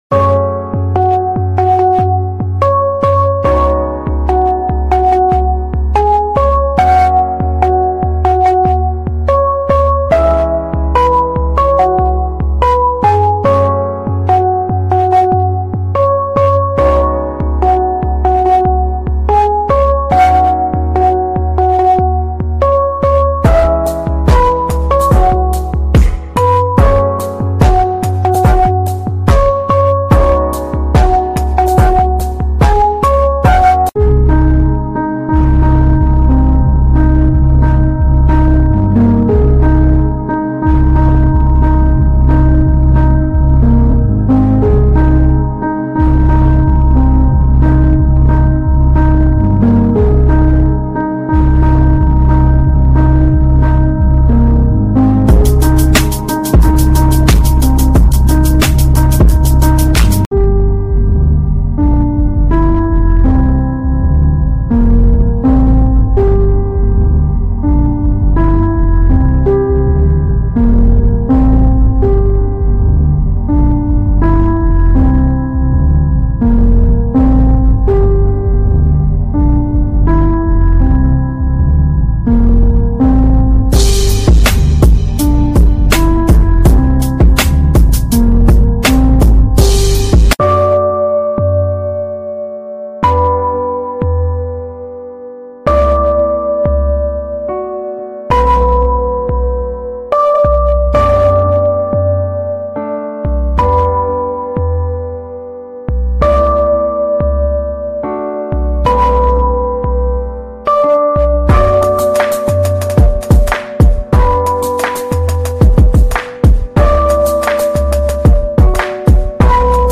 🇪🇬 Egyptian Bazaar Walking Tour sound effects free download